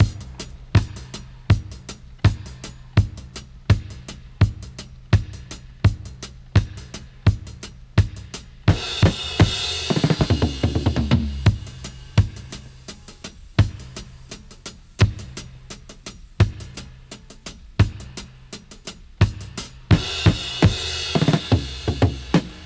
85-FX-01.wav